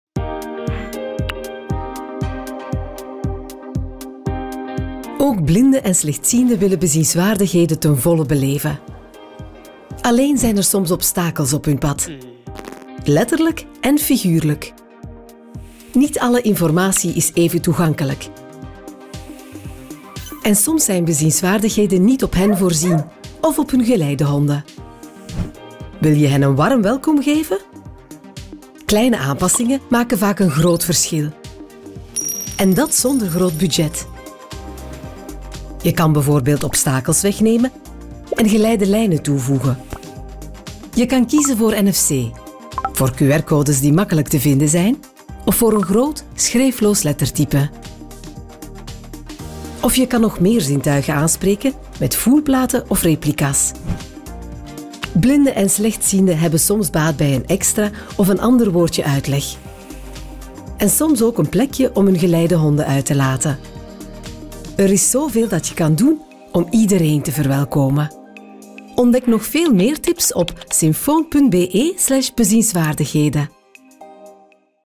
Tief, Natürlich, Zuverlässig, Freundlich, Warm
Unternehmensvideo